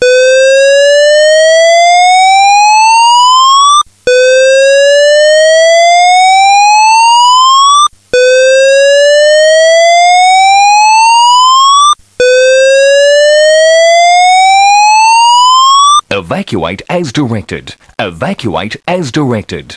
Announcement Tones
“Evacuation and voice” is the old AS220 Evacuation tones
Evac_and_voice_std.mp3